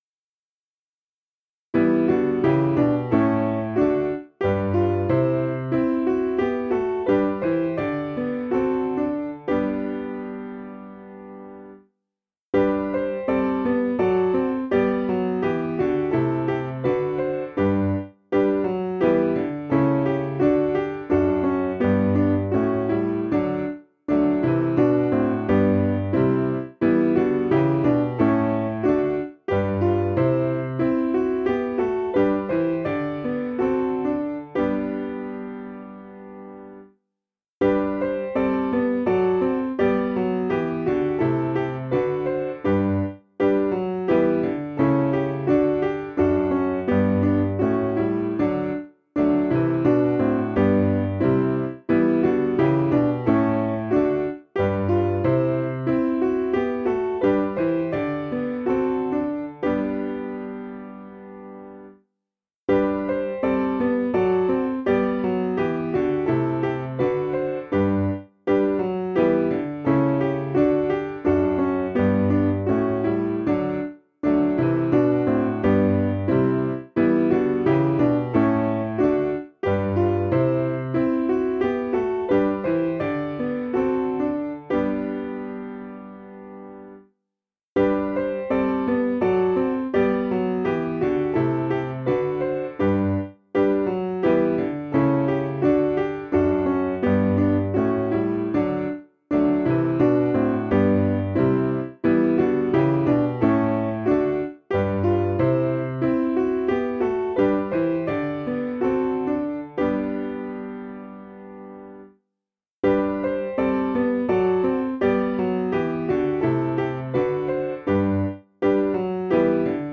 Information about the hymn tune IVYHATCH.